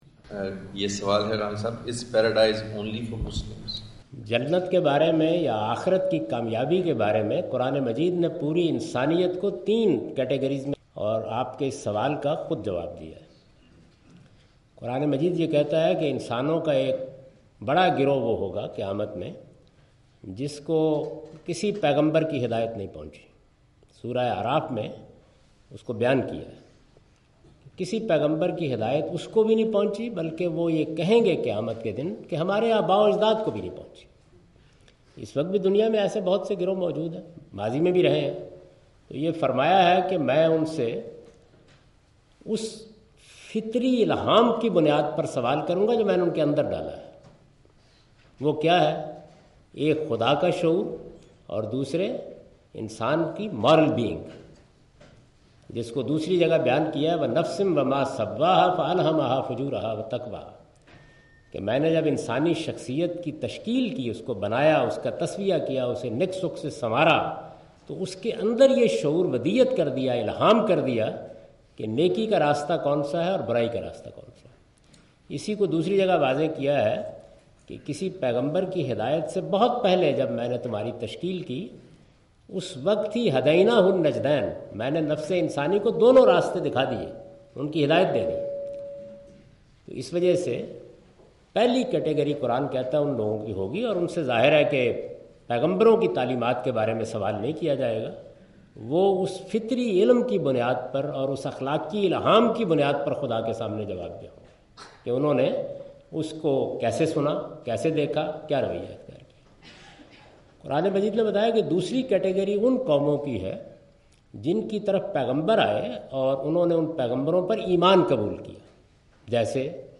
Javed Ahmad Ghamidi answers the question "Is Paradise only for Muslims?" during his Visit of Brunel University London in March 12, 2016.
جاوید احمد صاحب غامدی اپنے دورہ برطانیہ 2016 کےدوران برونل یونیورسٹی لندن میں "کیا جنت صرف مسلمانوں کے لیے ہے؟" سے متعلق ایک سوال کا جواب دے رہے ہیں۔